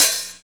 09.4 HAT.wav